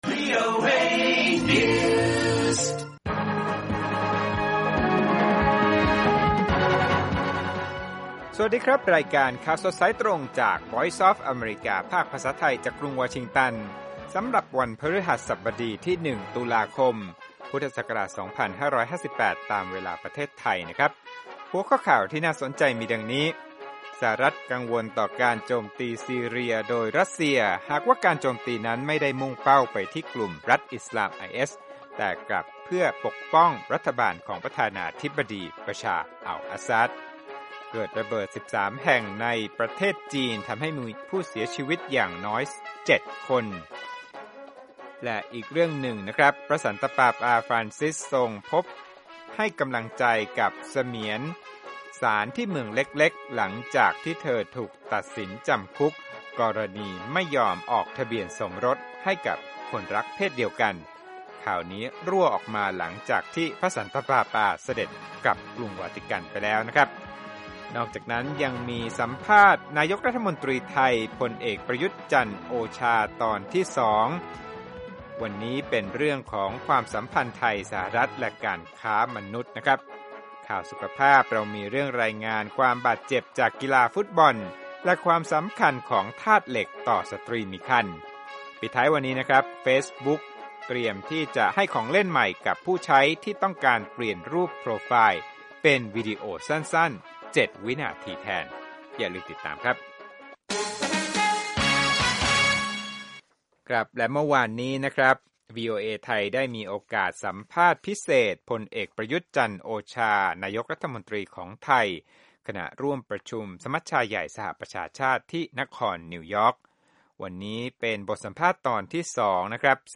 ข่าวสดสายตรงจากวีโอเอ ภาคภาษาไทย 6:30 – 7:00 น. วันพฤหัสบดี 1 ต.ค. 2558